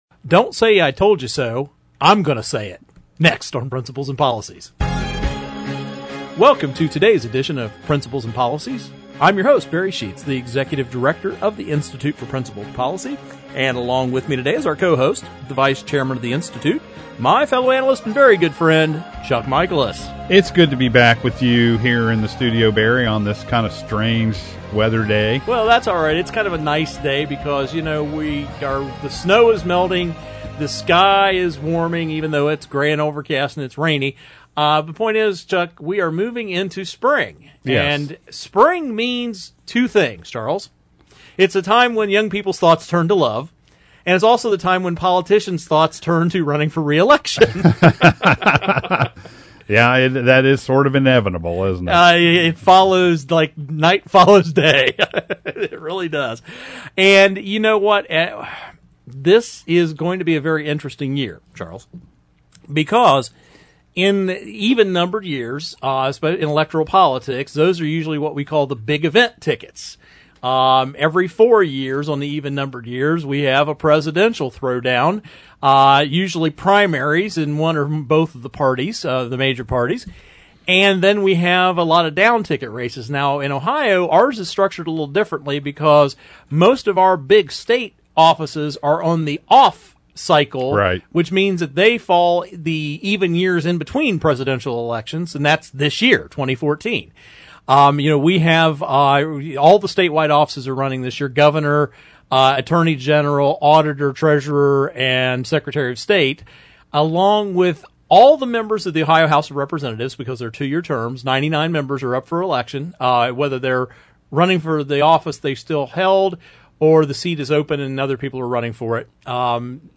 Our Principles and Policies radio show for Saturday February 22, 2014.